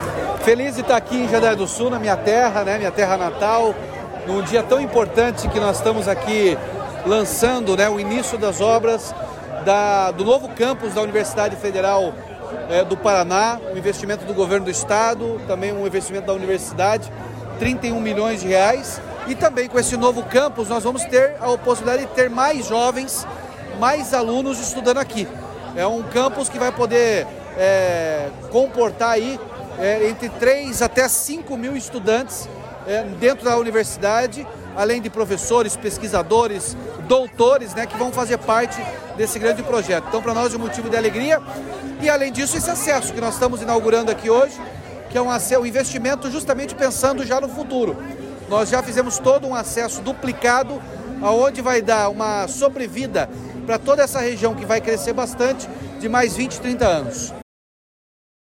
Sonora do governador Ratinho Junior sobre a assinatura da ordem de serviço para construção de sede da UFPR em Jandaia do Sul